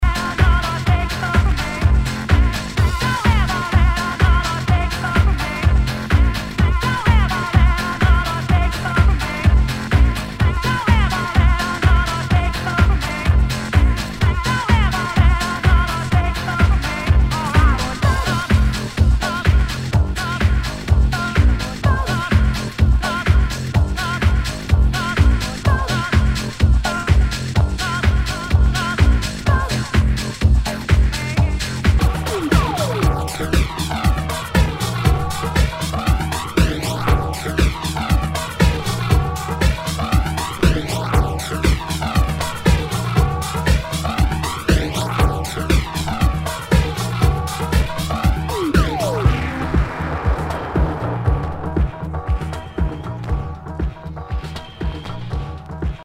HOUSE/TECHNO/ELECTRO
ナイス！ファンキー・ハウス！
全体にチリノイズが入ります